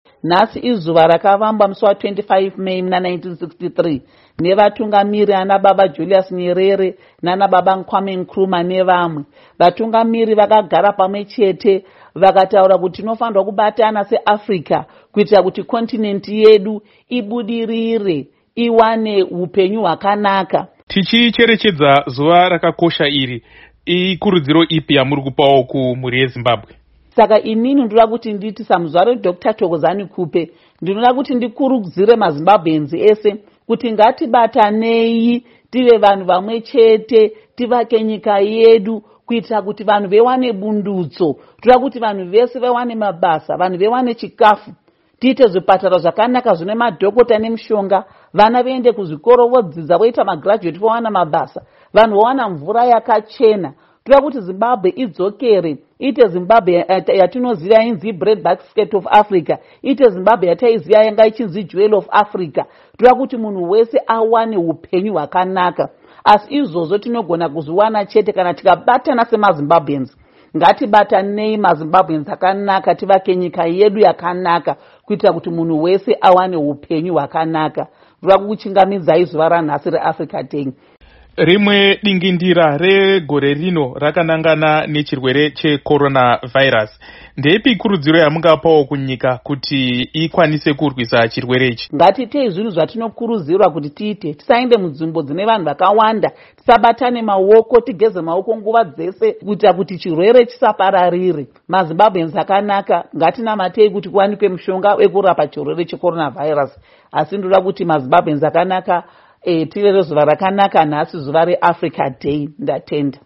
Hurukuro naDoctor Thokozani Khupe